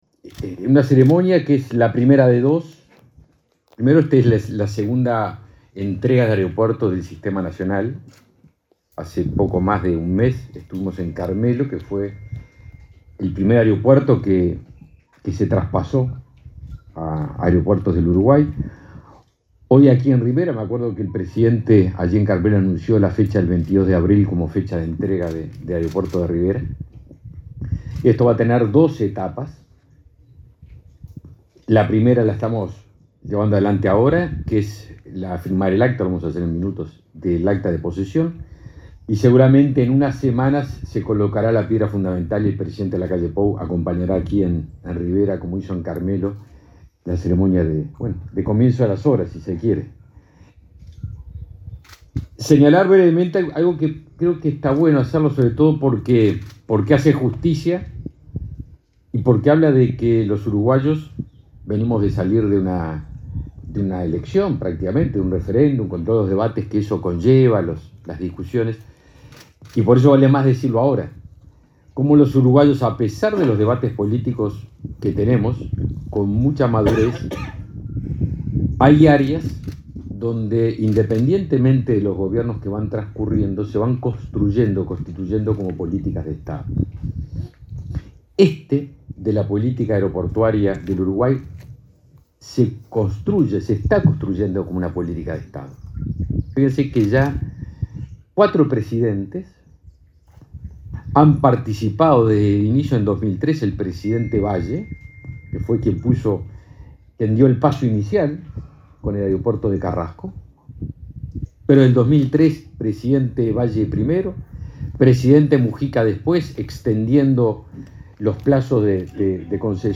Palabras del ministro de Defensa Nacional, Javier García
El ministro de Defensa Nacional, Javier García, participó este viernes 22 en el acto en el que la empresa Corporación América Airports se hizo cargo